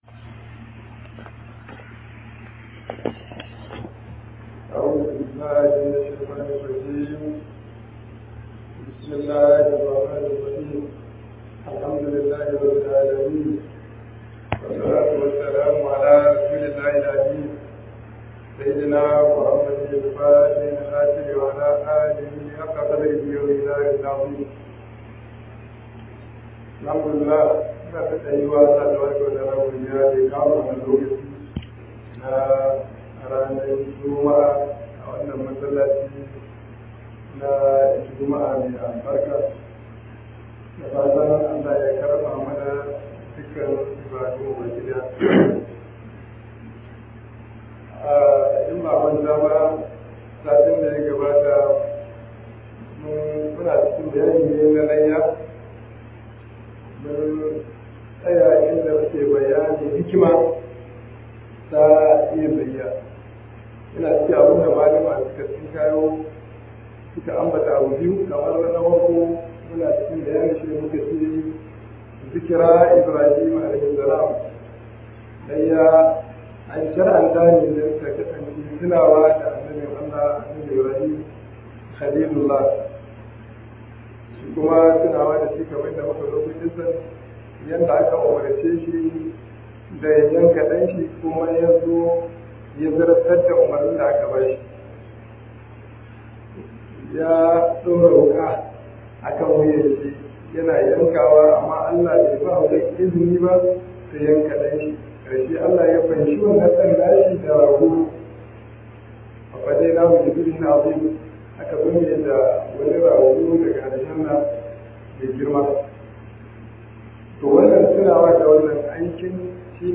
KHUDBAH JUMA-A
003 Wa-azin Juma-a.mp3